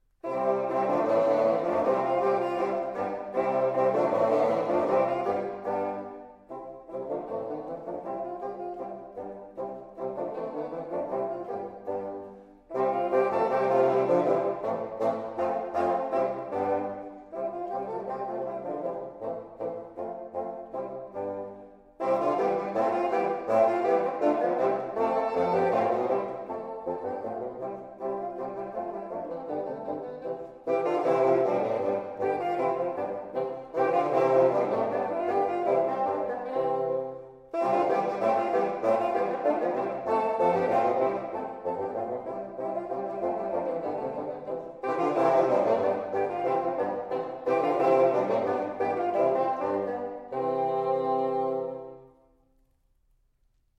Vielleicht spielte Susato ja auch Fagott - oder sogar das kleinere Fagottino, wie es auf der Aufnahme zu hören ist?
Erstaunlich, wie sie auch heute noch durch unverfälschte Spielfreude und lüpfige Rhythmen erfreuen!